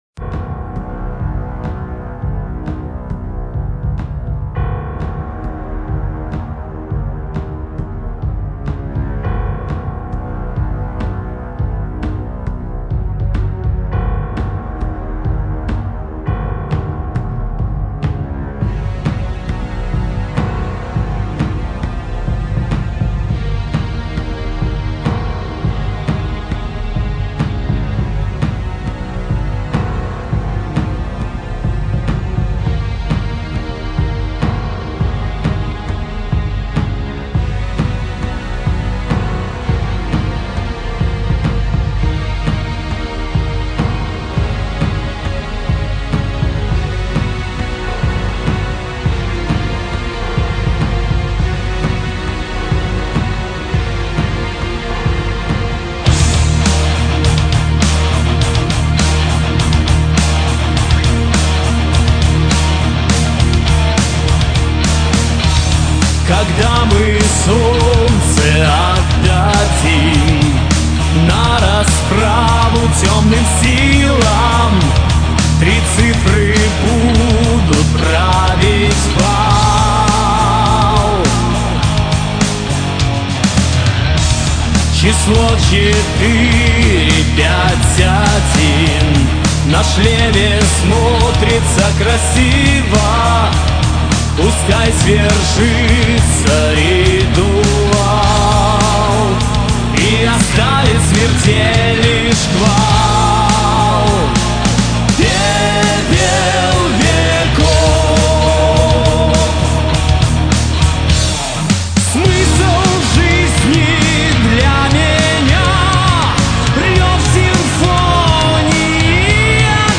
бас, вокал